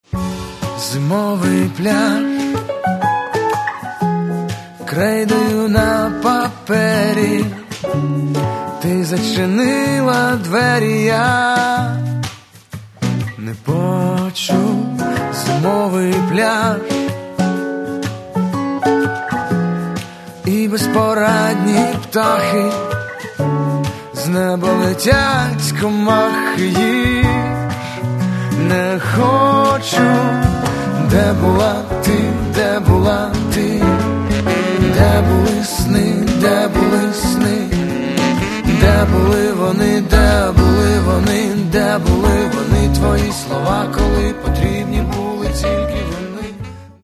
Каталог -> Рок и альтернатива -> Поэтический рок